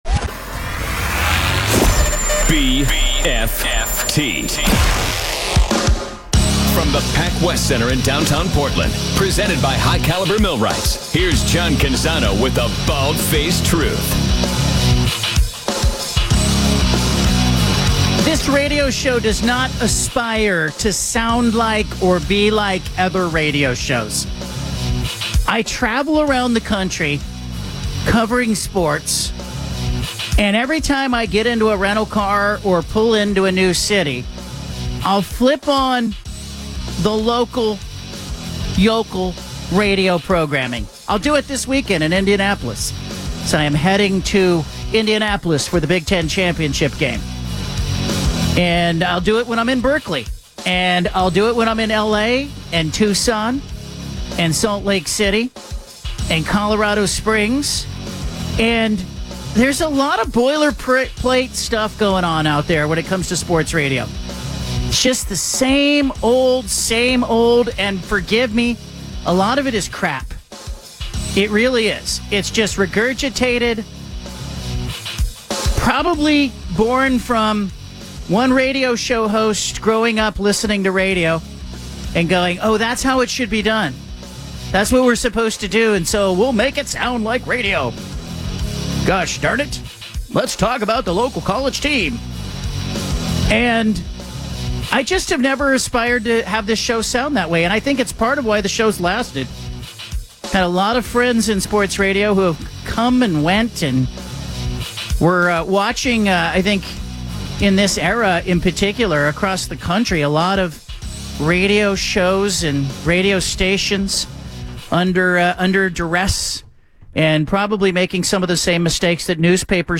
Later in the show, Oregon coach Dan Lanning joins.